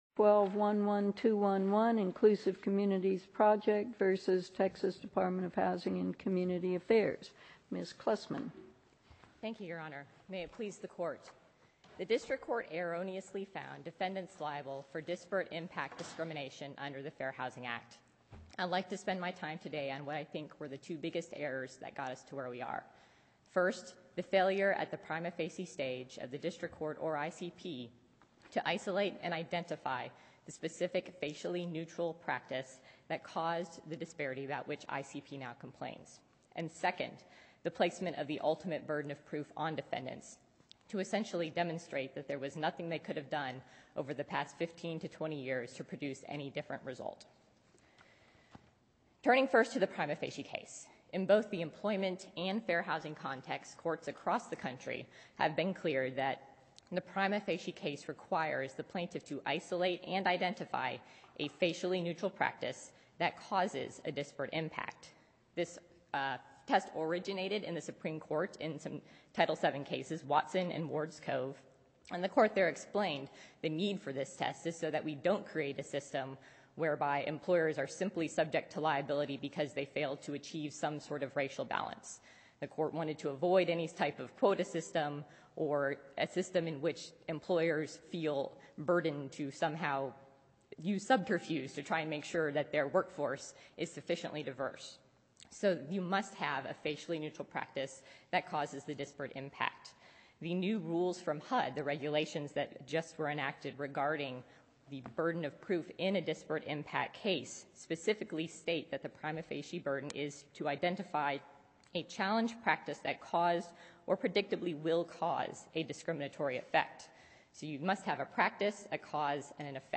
Texas housing tax credit fair housing case oral arguments before 5th Circuit Court include lively questions and answers - Texas Housers
Yesterday’s oral arguments in the State of Texas’ appeal of the District Court’s ruling in the Inclusive Communities Project vs. Texas Department of Housing and Community Affairs before the 5th Circuit Court of Appeals are now available as an audio recording.